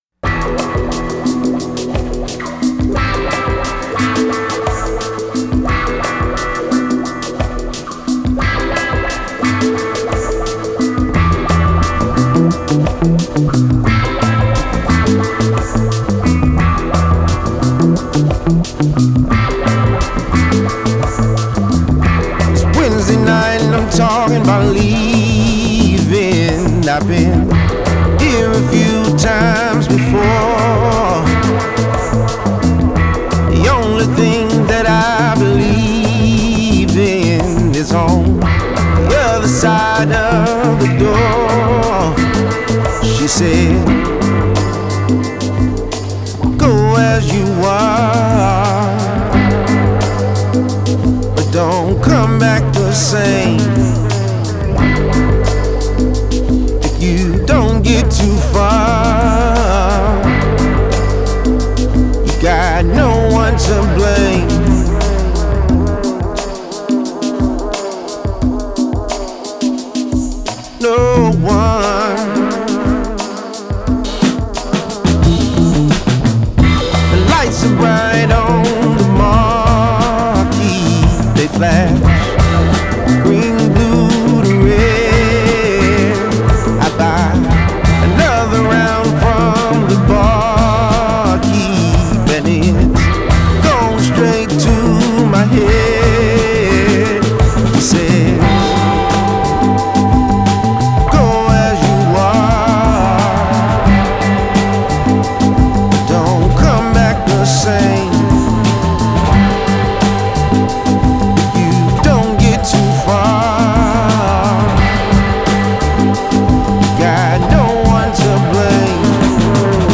atmospheric, paranoid and moody as hell
vapour trail vocals tapering off and out into the night.